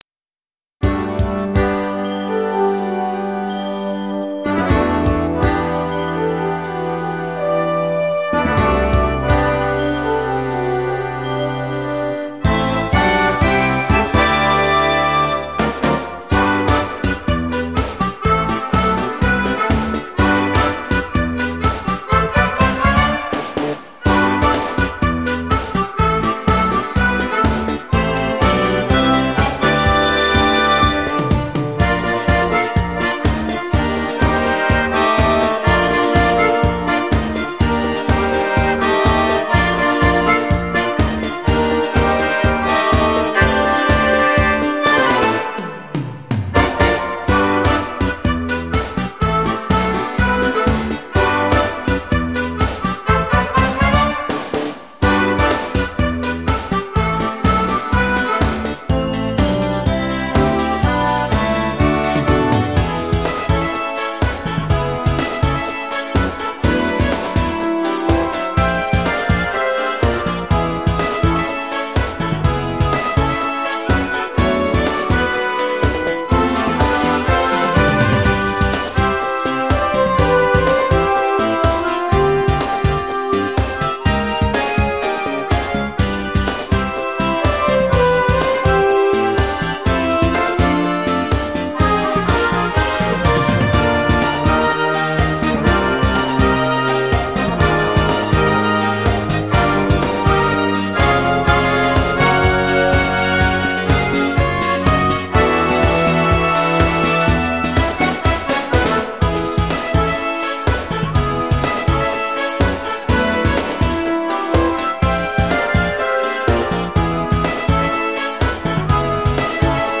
Symphonic Rockfeeling